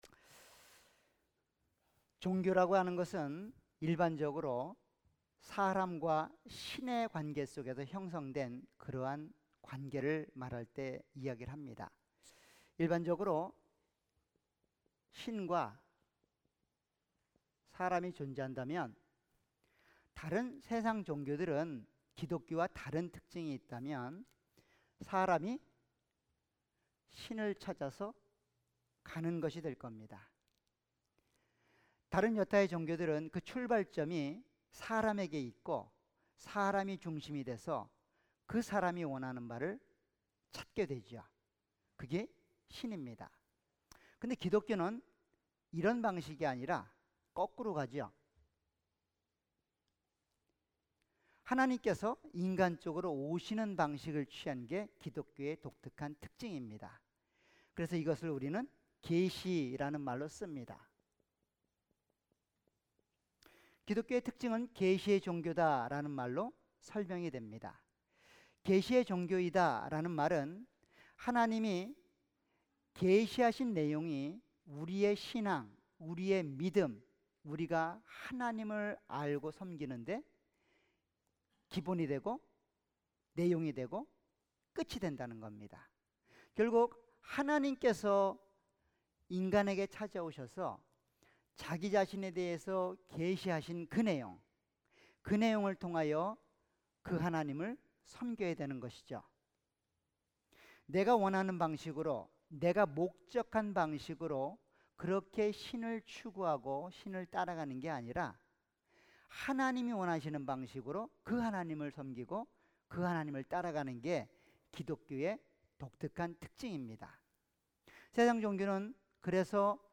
All Sermons
Series: 수요예배.Wednesday